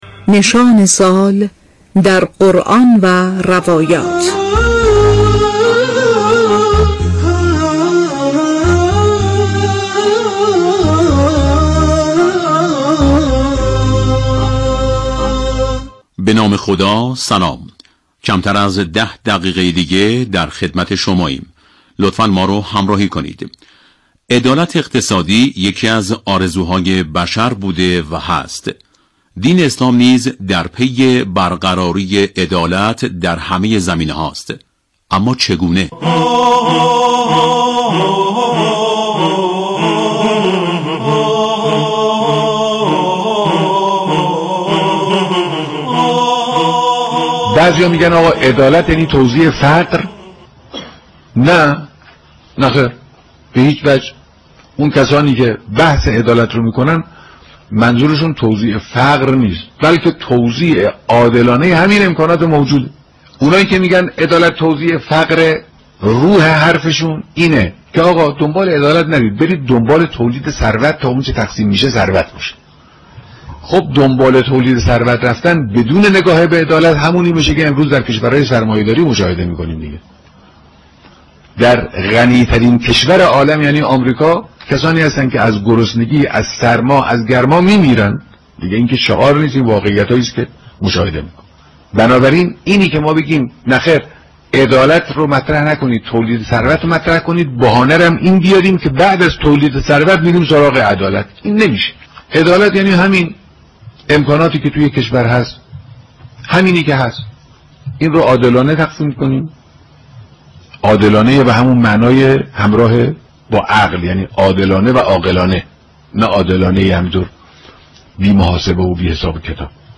به گزارش ایکنا، «نشان» عنوان میان‌برنامه شبکه رادیویی قرآن است که به مدت 10 دقیقه با هدف تبیین شعار  سال در قرآن و روایات به روی آنتن این شبکه رادیویی می‌رود.
یادآور می‌شود، میان‌برنامه نشان عصرها از ساعت 14:30 به روی آنتن رادیو قرآن موج اف ام ردیف ۱۰۰ مگاهرتز می‌رود.